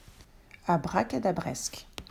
5. Abracadabresque: unglaubhaft, fragwürdig (abrakadabresk)